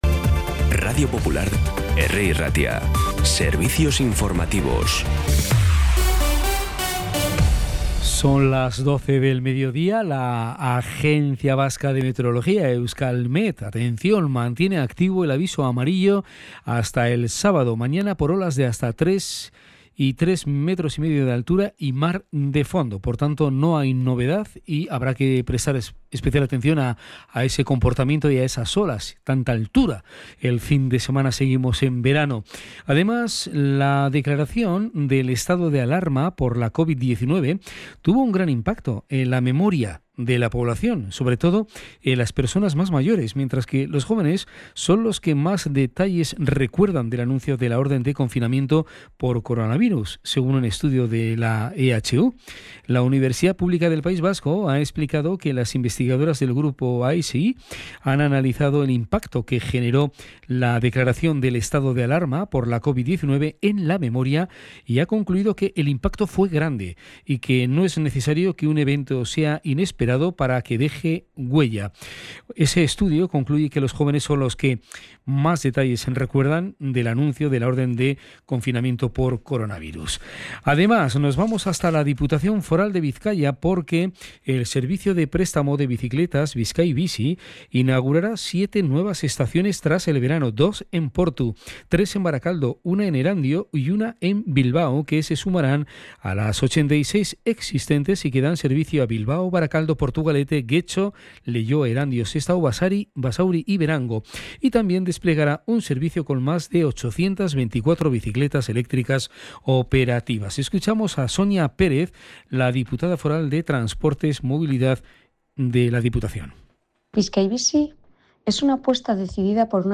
Las noticias de Bilbao y Bizkaia del 29 de agosto a las 12
Los titulares actualizados con las voces del día.